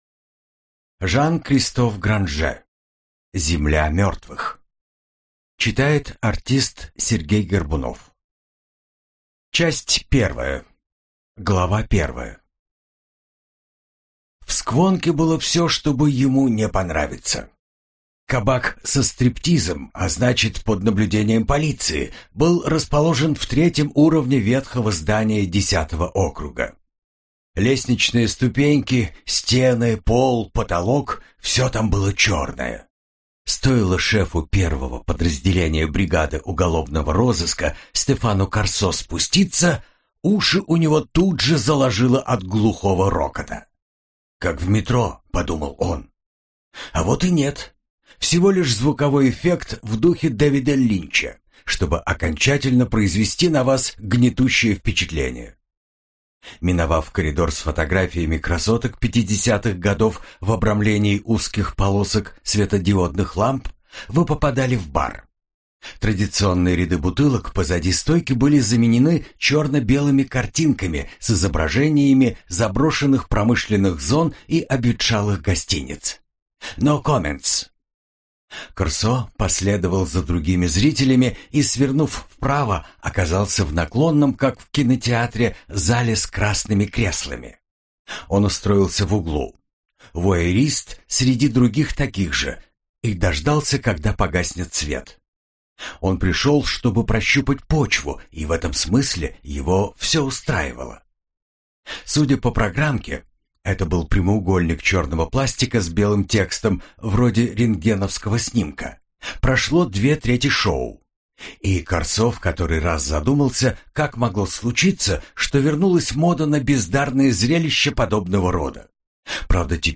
Аудиокнига Земля мертвых - купить, скачать и слушать онлайн | КнигоПоиск